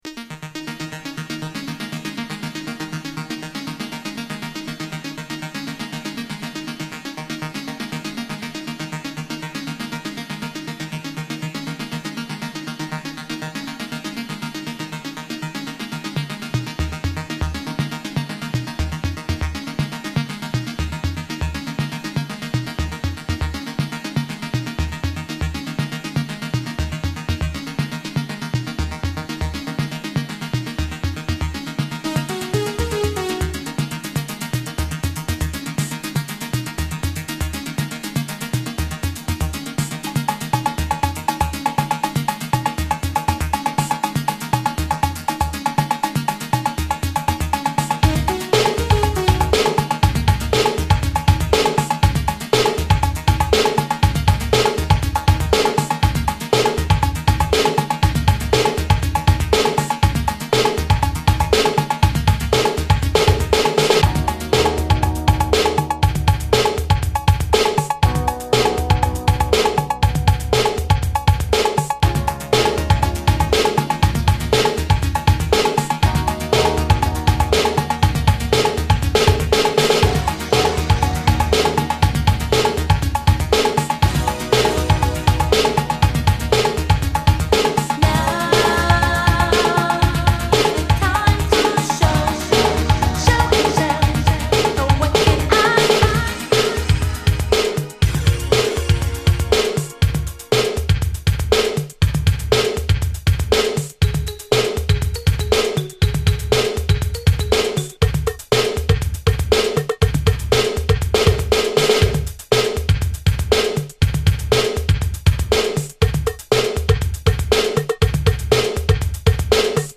DISCO
ギター・カッティングをダビーに配した
ビキビキとシンセ・フレーズがダークに響く